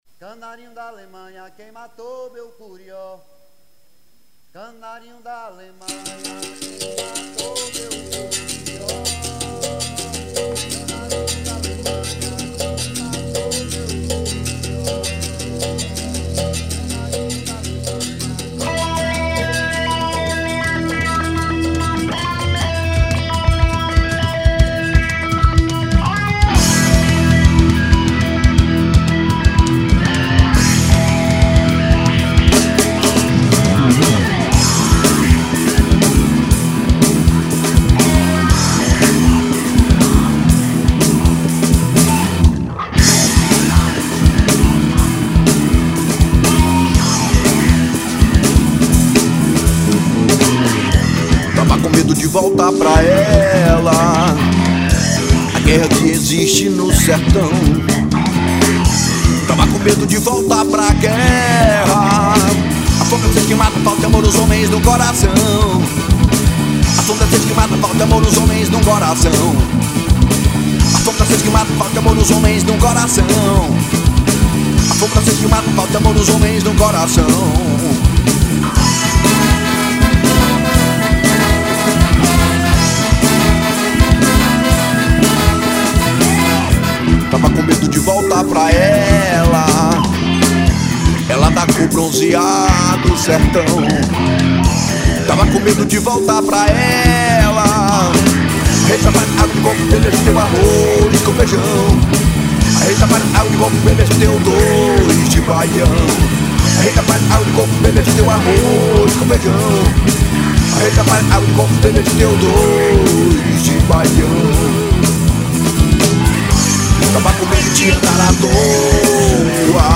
1957   04:04:00   Faixa:     Rock Nacional